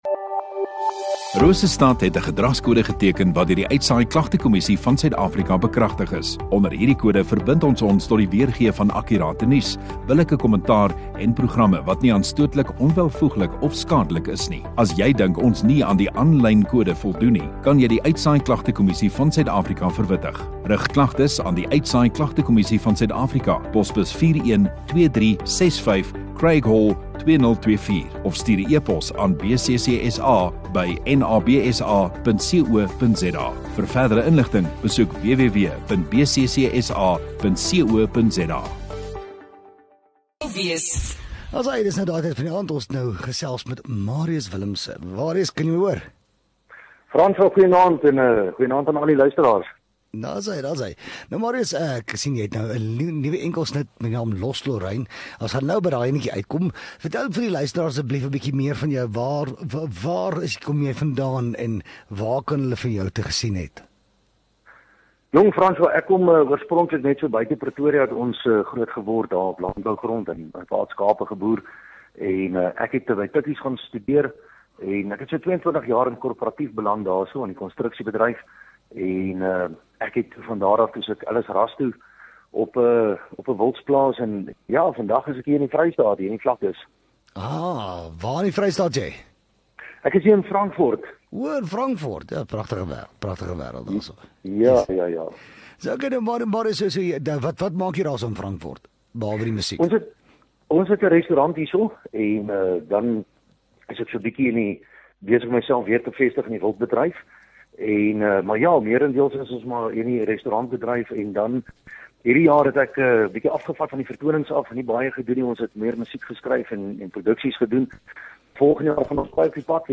View Promo Continue Radio Rosestad Install Vermaak en Kunstenaars Onderhoude 24 Nov Nuwe enkelsnit